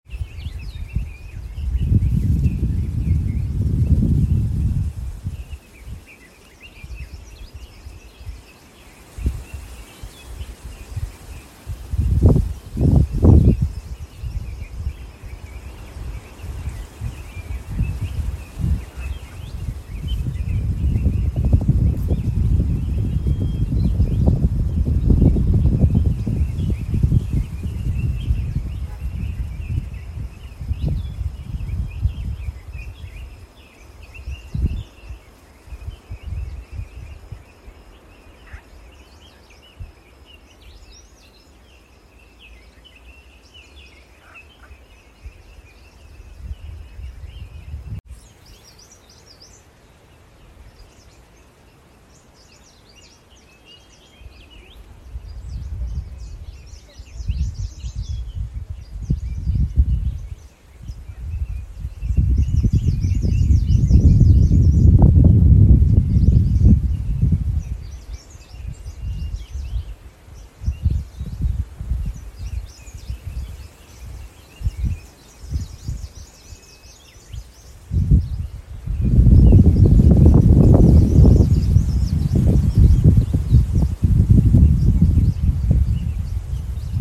大围山国家森林公园浏阳河源头鸟鸣声
大围山浏阳河源头鸟叫声